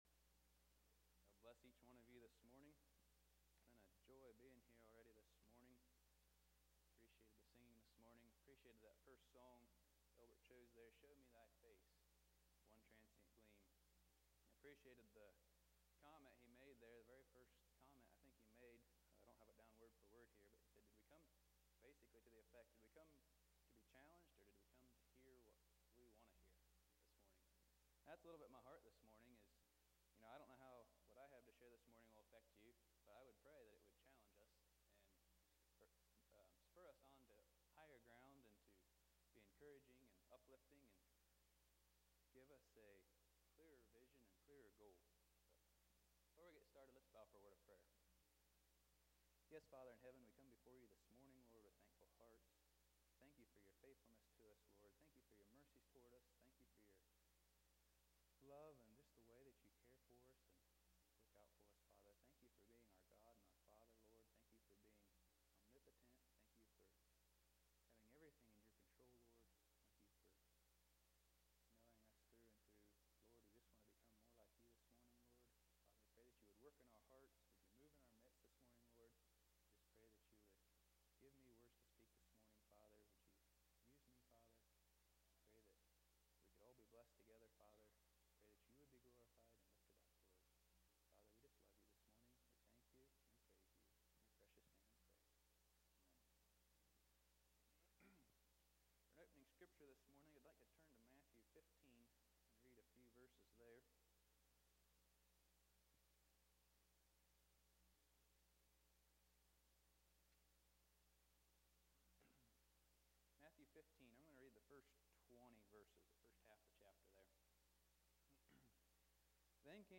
2024 Sermons - Dayton Christian Fellowship